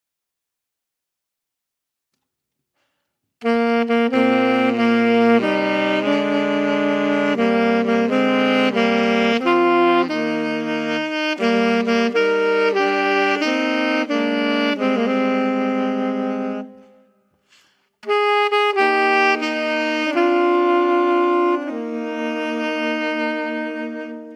vibrant and festive